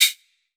TC2 Perc3.wav